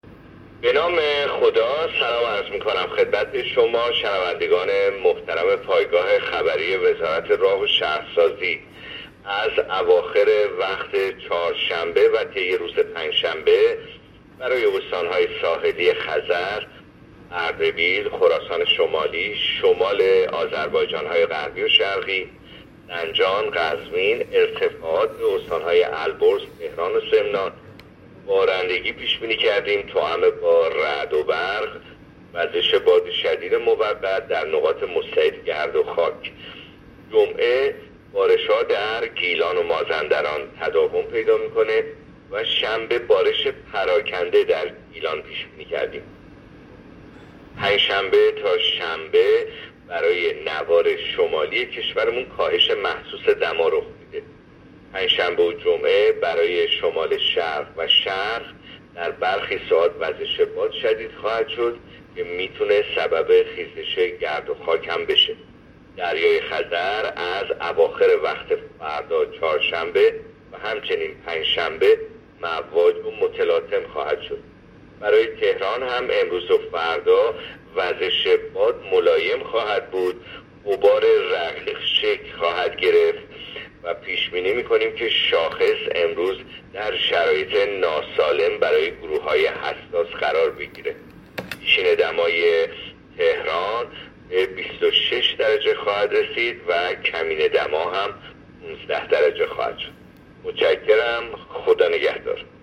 گزارش رادیو اینترنتی پایگاه‌ خبری از آخرین وضعیت آب‌وهوای ششم آبان؛